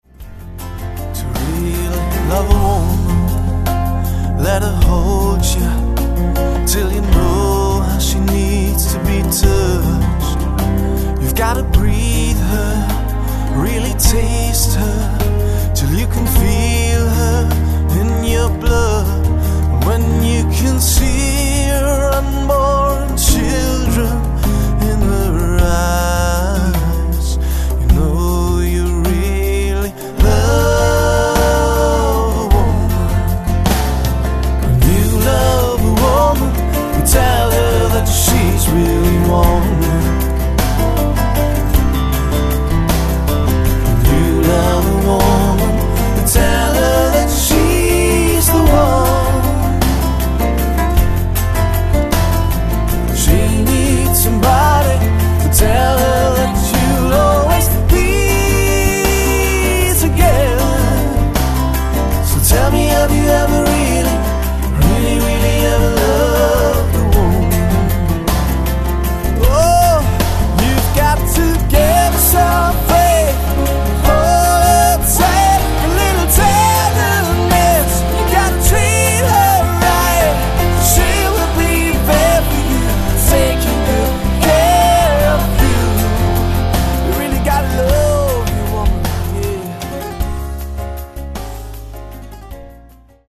plakativ, markant, sehr variabel
Mittel minus (25-45)
Vocals (Gesang)